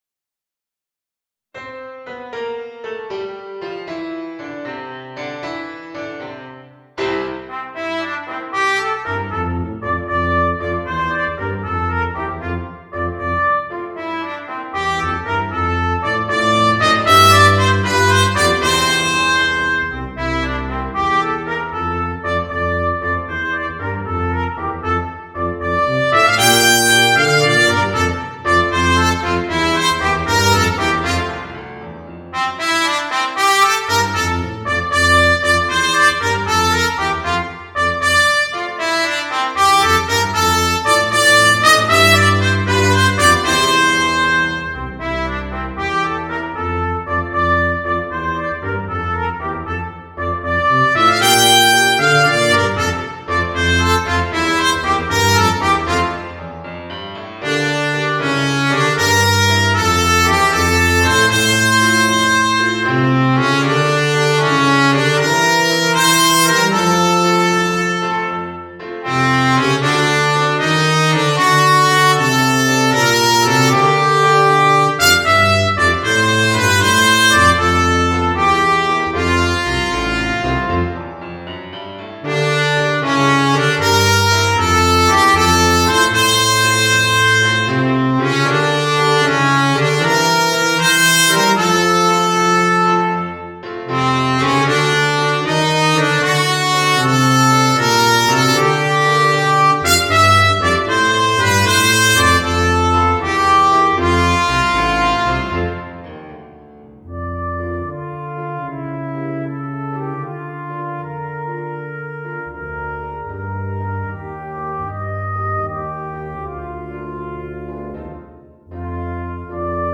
金管二重奏+ピアノ